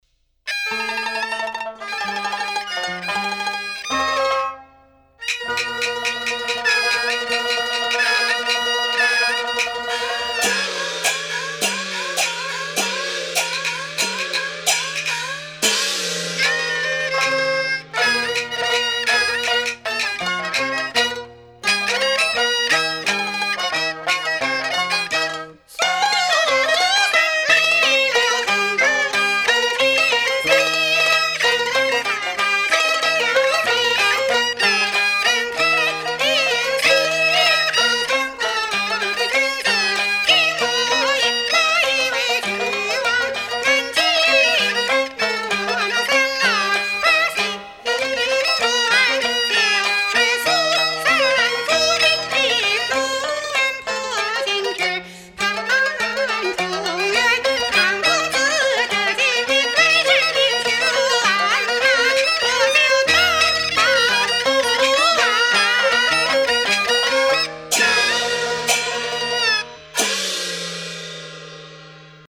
音樂類型：中國傳統戲曲